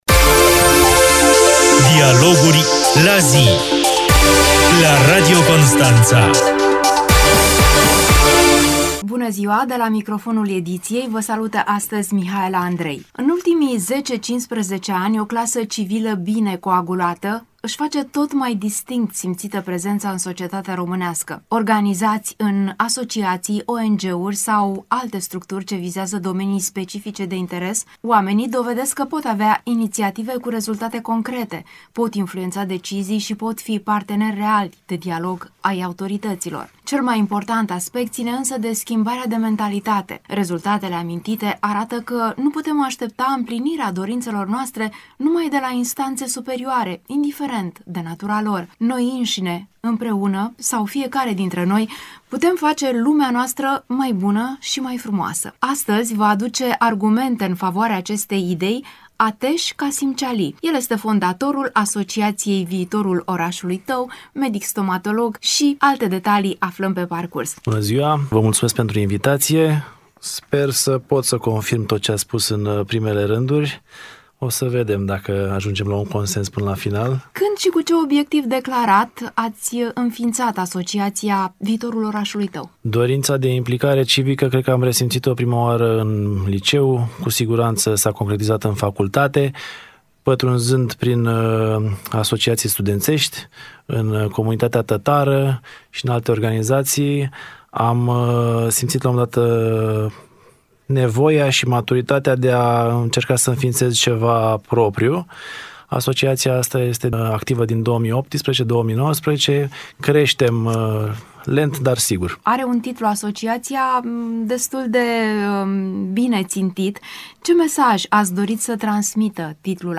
invitat în emisiunea de astăzi